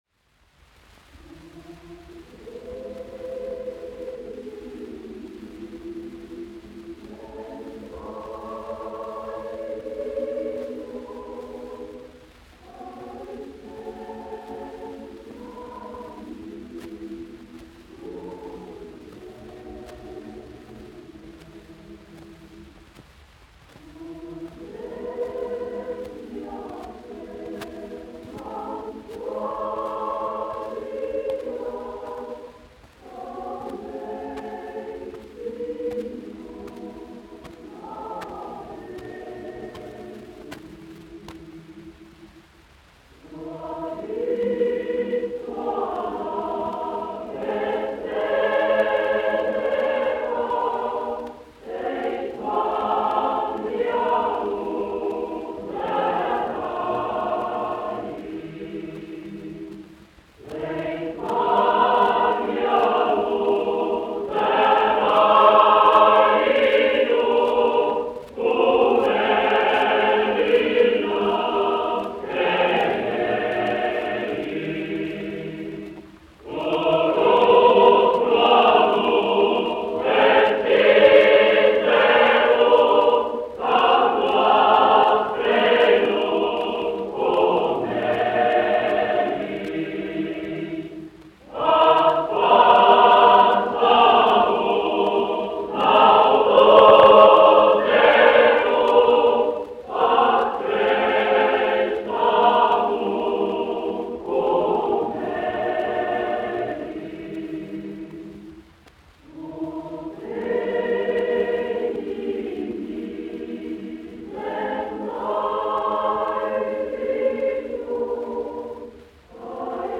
Latvijas Nacionālā opera. Koris, izpildītājs
1 skpl. : analogs, 78 apgr/min, mono ; 25 cm
Latviešu tautasdziesmas
Kori (jauktie)
Latvijas vēsturiskie šellaka skaņuplašu ieraksti (Kolekcija)